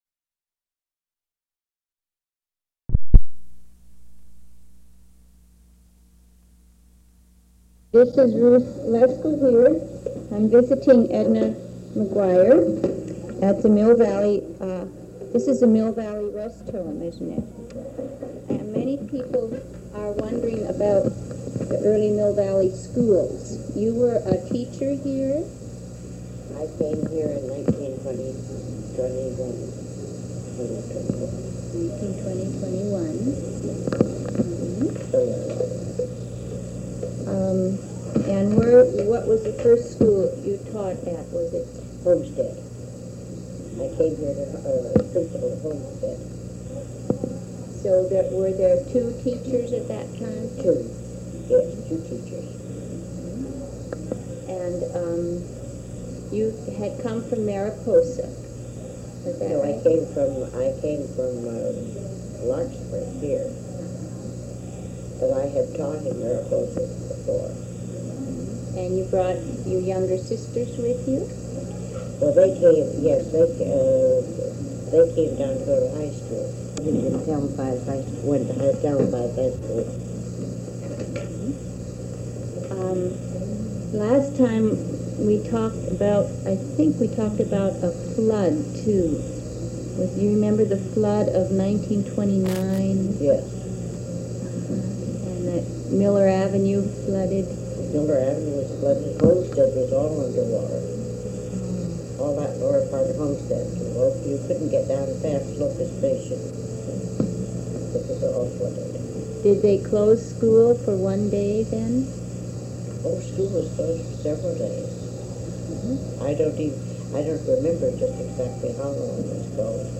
Editor's Note: The transcript for this oral history differs somewhat from the audio recording due to editorial decisions at the time of the recording. Unfortunately, the sound quality of the recording is poor.